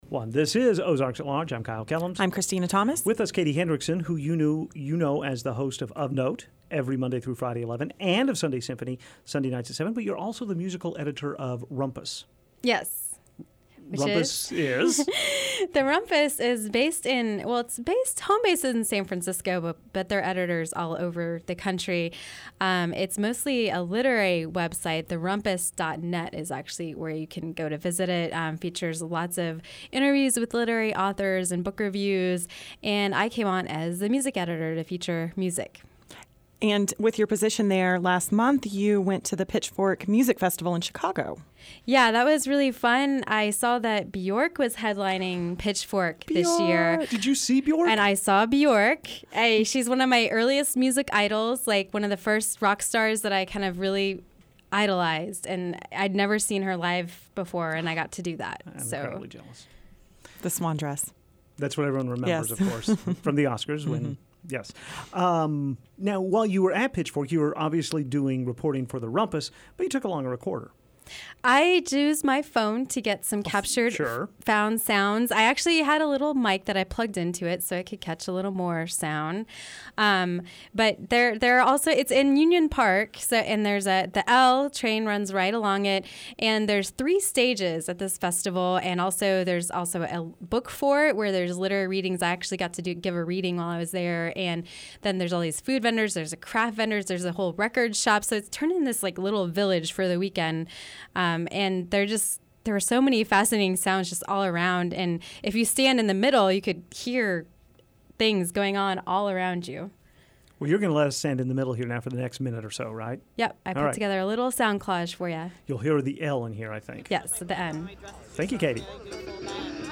Sounds From the Festival Village